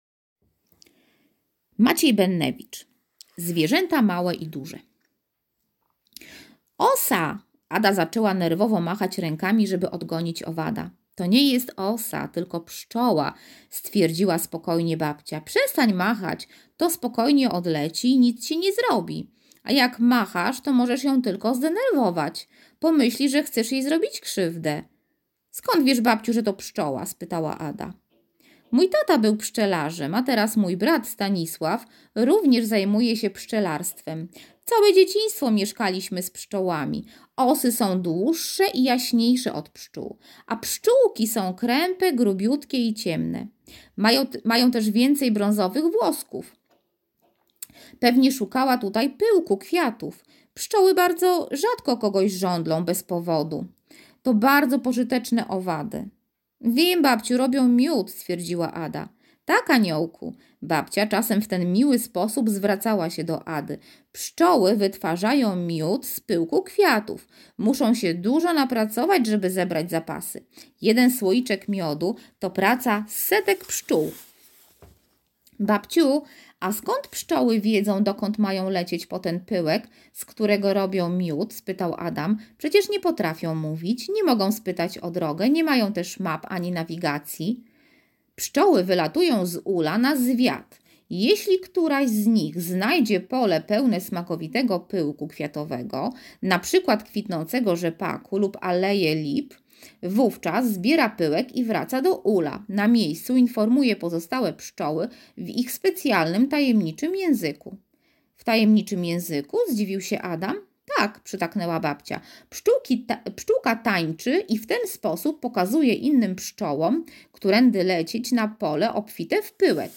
Na początek wysłuchajcie nagranego przeze mnie opowiadania pt.” Zwierzęta małe i duże” i zapamiętajcie czym różni się pszczoła od osy,jakie zwierzęta mają podobny kolor skóry,dlaczego konie noszą takie dziwne nazwy…